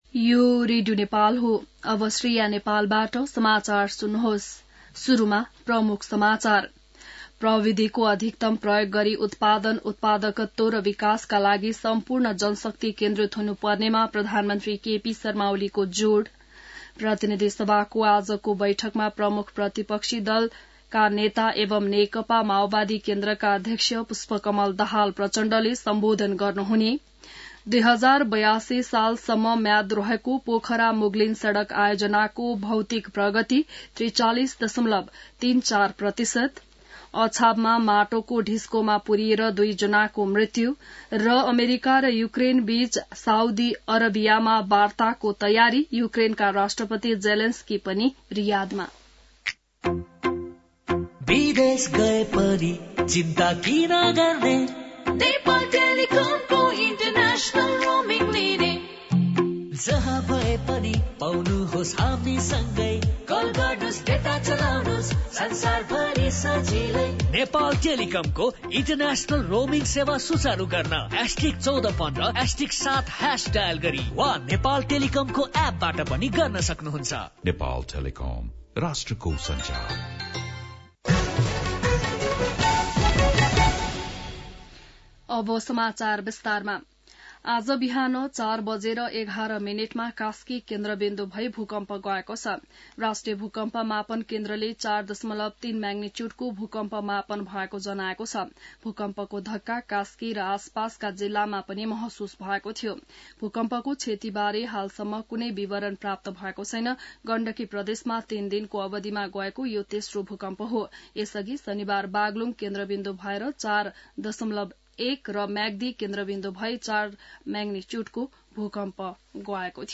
बिहान ७ बजेको नेपाली समाचार : २८ फागुन , २०८१